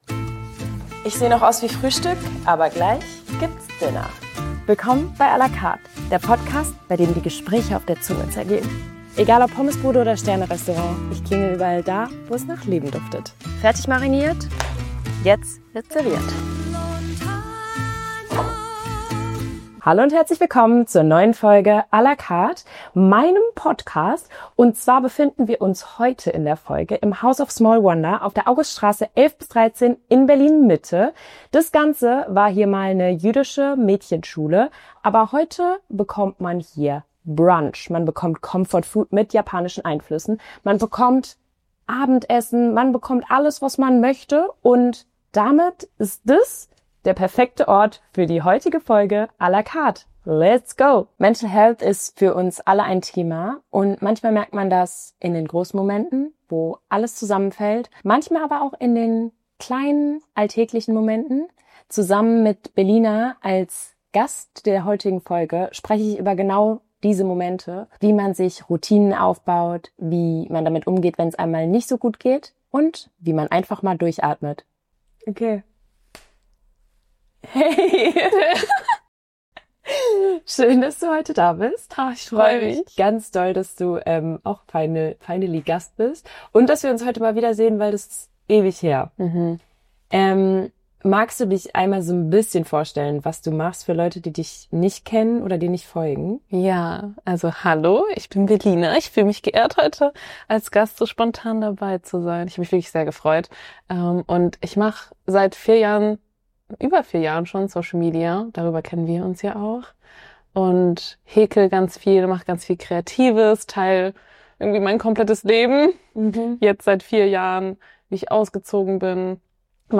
Bei einer Tasse Cappuccino und leckerem Essen sprechen die beiden in der siebten Folge „à la Carte“ über mentale Gesundheit